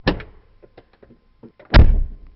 دانلود آهنگ در 2 از افکت صوتی اشیاء
دانلود صدای در 2 از ساعد نیوز با لینک مستقیم و کیفیت بالا
جلوه های صوتی
برچسب: دانلود آهنگ های افکت صوتی اشیاء دانلود آلبوم صدای باز و بسته شدن درب از افکت صوتی اشیاء